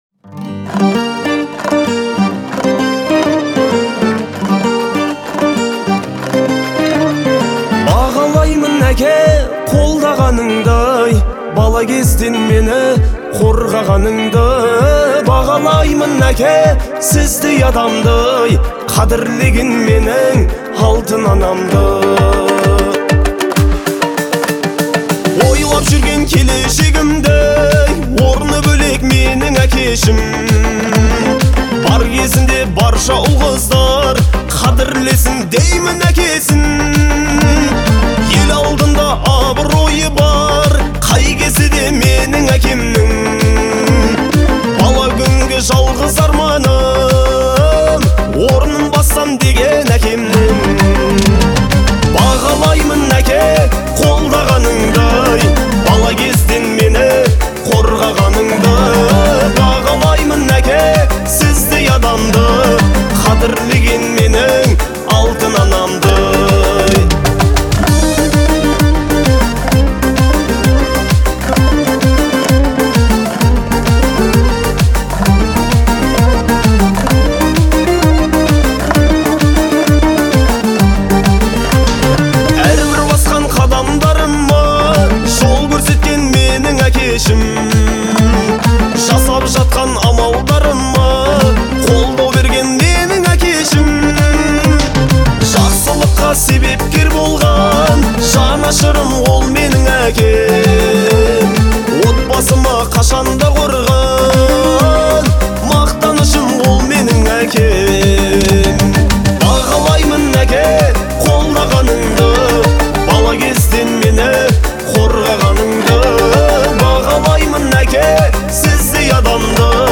Трек размещён в разделе Казахская музыка.